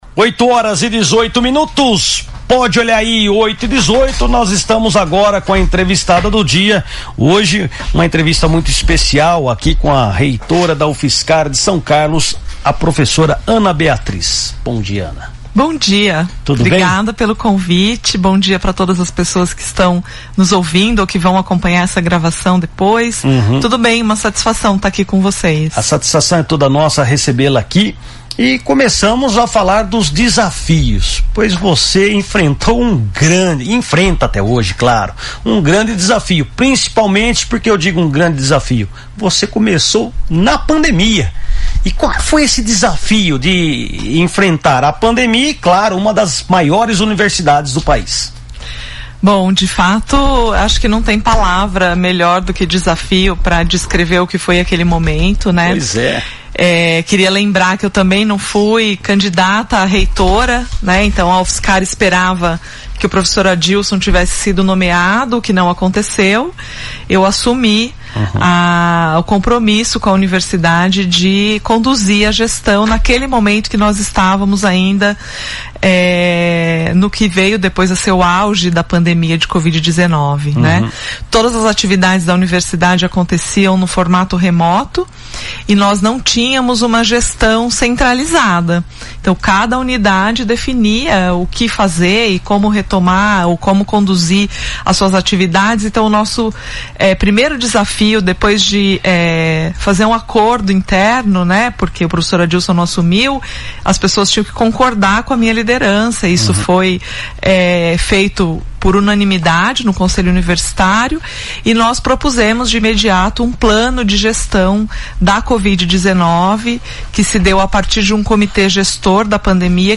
Em uma entrevista concedida ao programa Primeira Página no Ar, da Rádio São Carlos FM 107.9, na manhã desta quarta-feira, 05, a reitora da Universidade Federal de São Carlos (UFSCar), Profa. Dra. Ana Beatriz de Oliveira, fez um amplo balanço de sua gestão e revelou os principais desafios e conquistas da instituição.